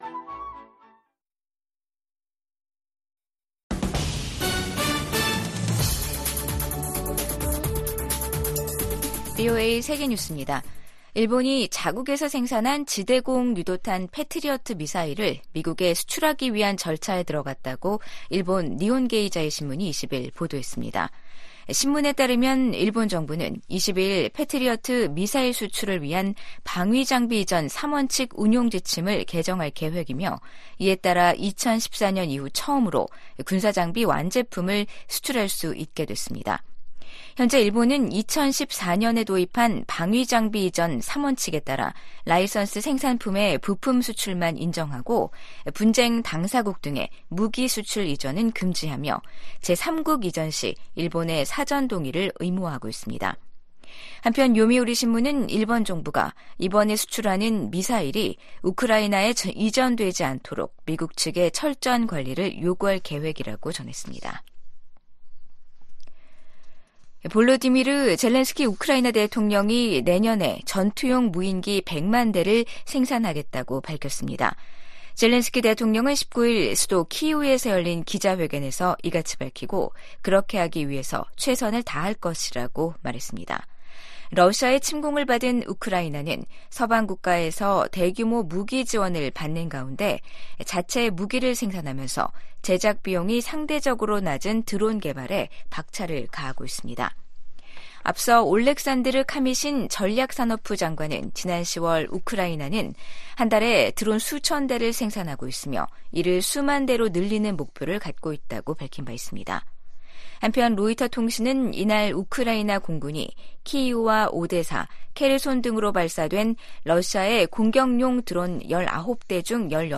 VOA 한국어 간판 뉴스 프로그램 '뉴스 투데이', 2023년 12월 20일 2부 방송입니다. 유엔 안보리가 북한 대륙간탄도미사일(ICBM) 발사 대응 긴급회의를 개최했지만 구체적 대응 조치에 합의하지 못했습니다. 유럽연합(EU)은 북한의 연이은 탄도미사일 발사를 국제 평화에 대한 위협으로 규정하며 강력히 규탄했습니다.